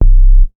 MoogSub 007.WAV